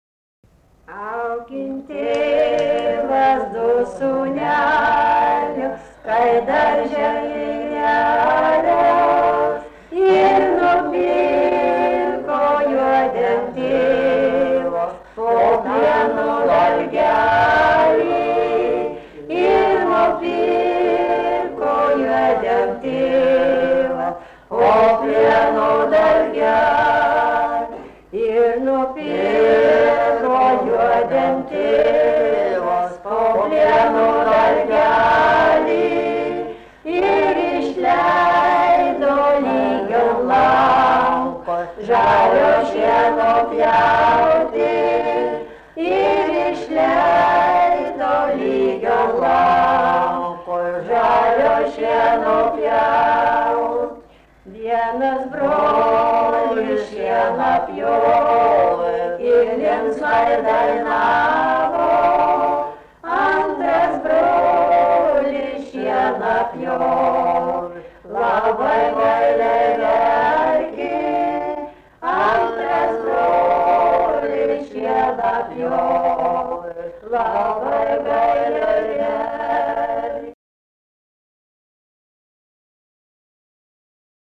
Šunkariai
vokalinis